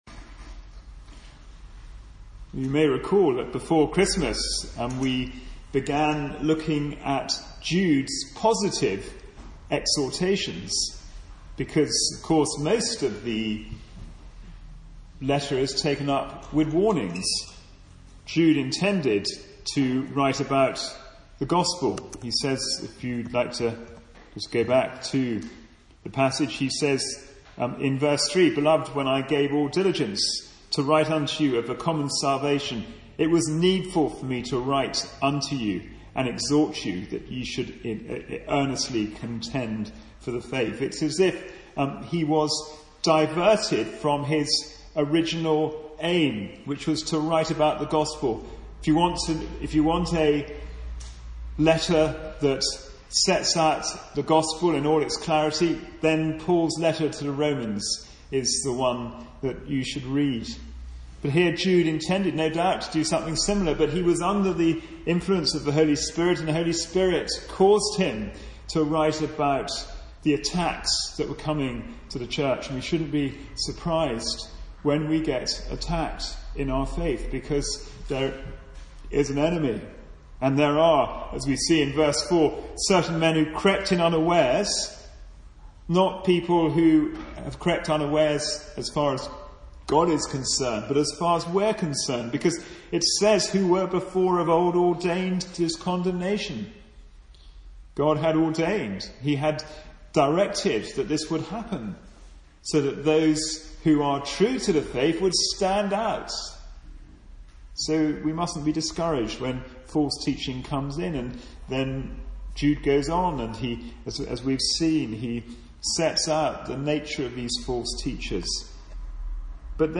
Jude Passage: Jude 1:21-25 Service Type: Sunday Morning Service « “Be ye also ready” Living a Life of Brokenness in a Time of Crisis »